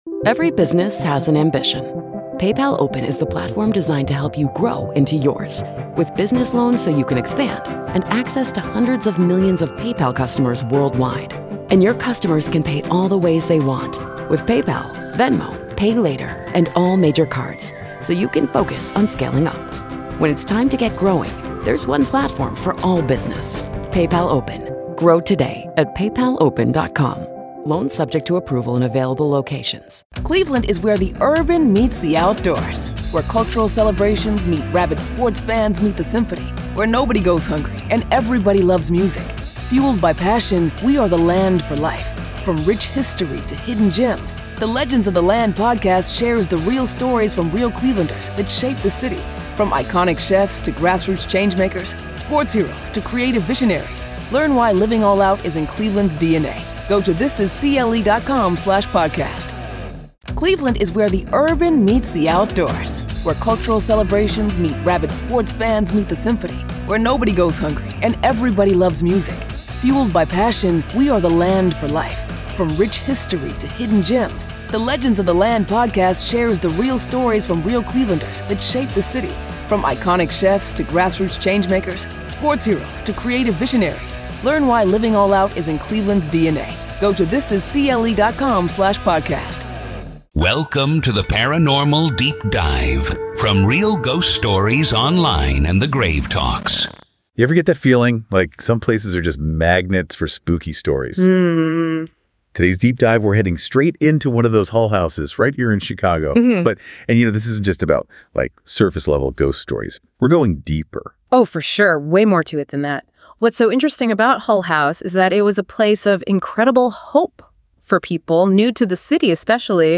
Deep Dive DISCUSSION!